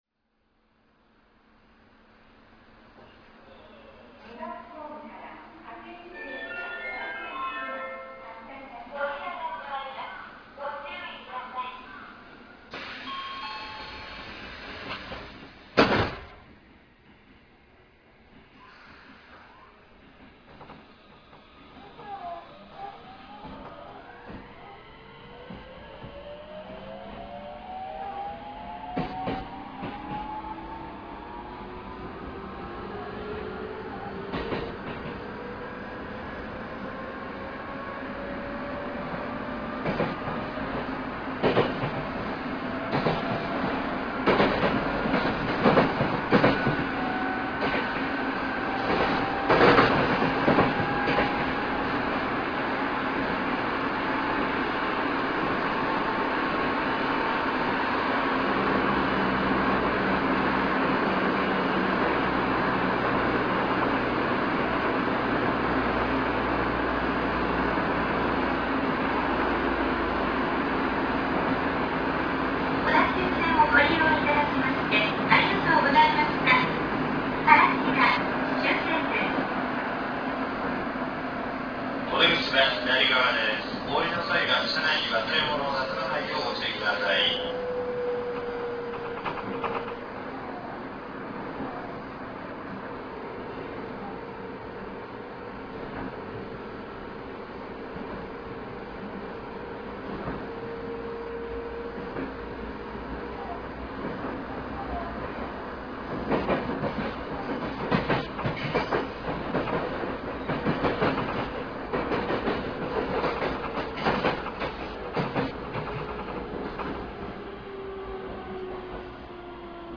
3次車走行音[oer2000e.ra/285KB]
ドアが閉まるときの大きな音は変わりありませんが、 小田急の車両としては初めての「乗降促進」が搭載されています。そのメロディーが鳴った時のサウンドを 掲載しておきます。ちなみに2054×8が運用についた初日の早朝の収録です。